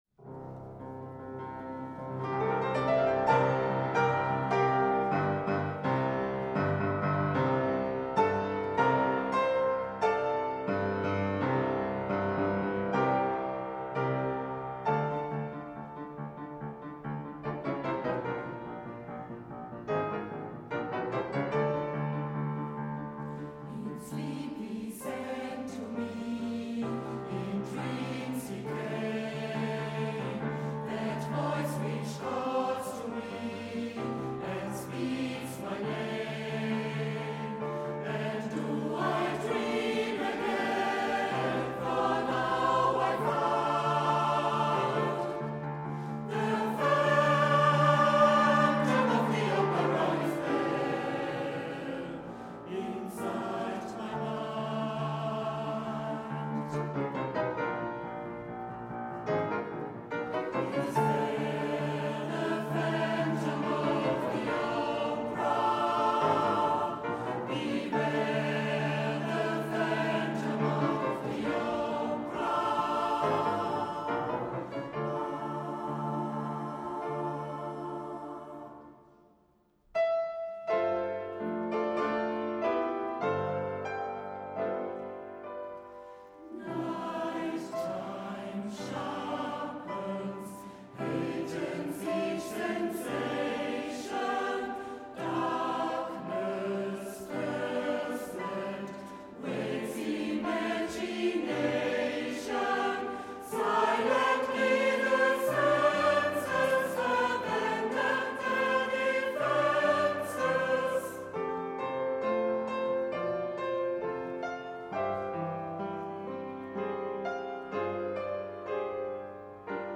Konzertmitschnitt vom 5.Juli 2009